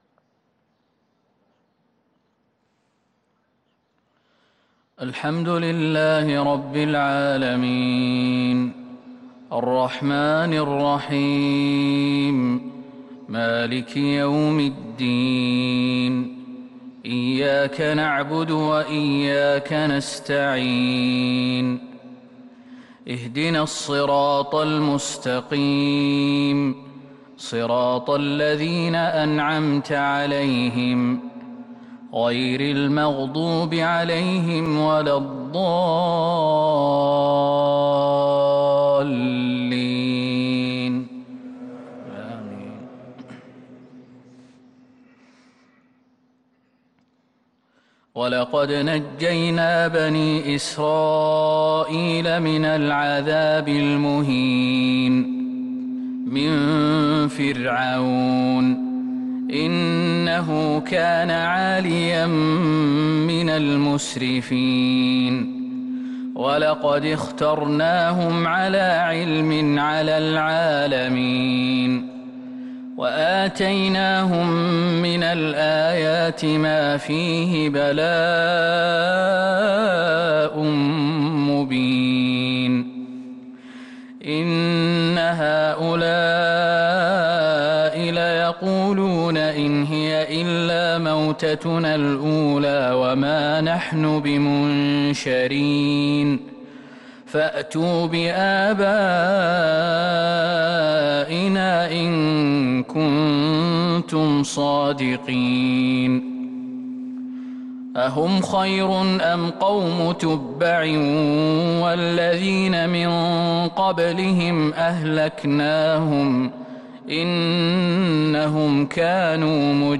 صلاة الفجر للقارئ خالد المهنا 4 صفر 1443 هـ